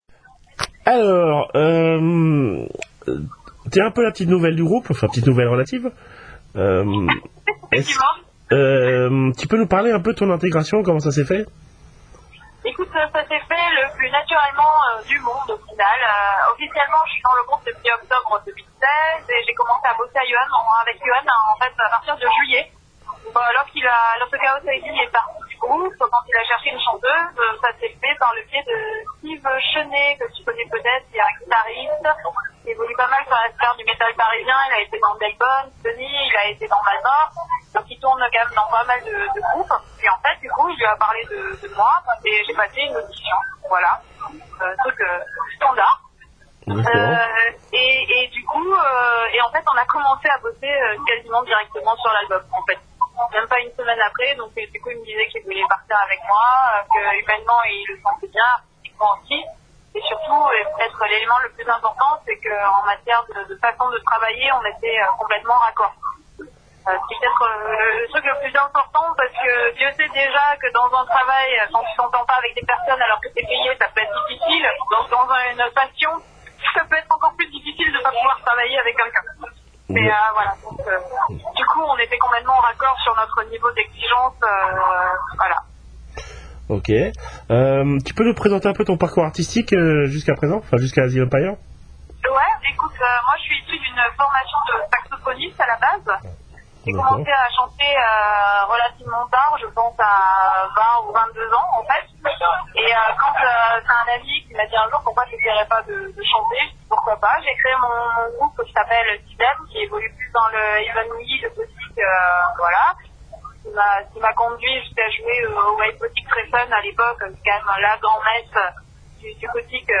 ASYLUM PYRE (interview